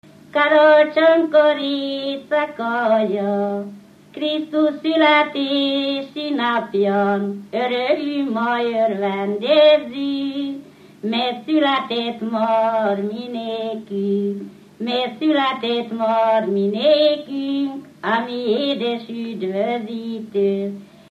Felföld - Nógrád vm. - Egyházasgerge
ének
Műfaj: Karácsonyi köszöntő
Stílus: 7. Régies kisambitusú dallamok
Kadencia: 1 (1) 5 1